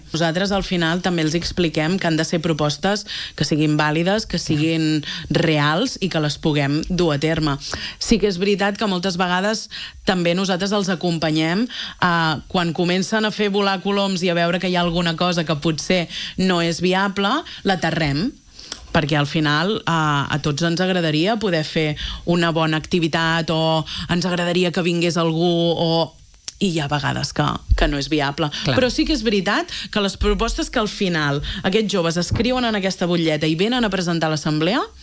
La regidora de Joventut i Participació, Mariceli Santarén, n’ha parlat a l’entrevista del matinal de RCT.